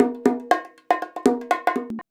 119BONG14.wav